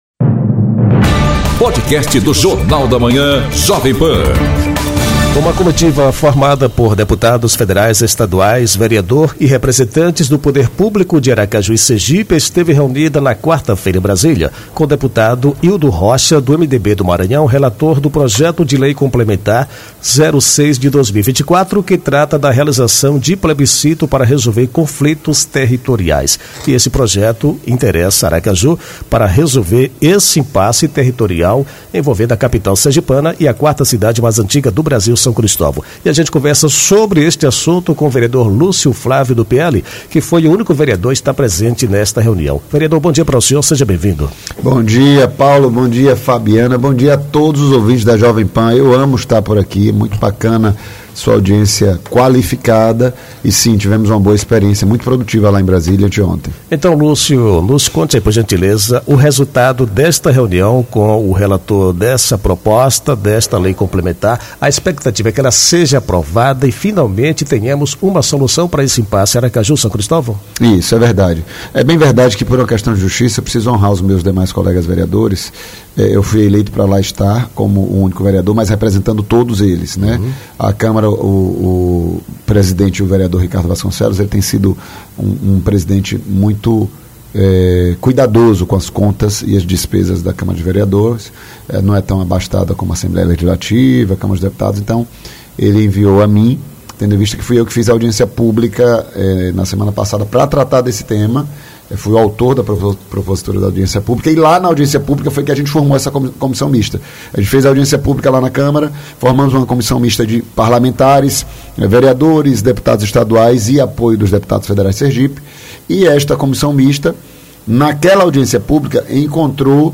Entrevista com o vereador Lúcio Flávio, vice-lider da prefeita Emília Corrêa. Ele fala sobre reunião na Câmara Federal para definir o impasse entre Aracaju e São Cristóvão, além de anunciar que levará a vereadora Sônia Meire ao Conselho de Ética da Câmara.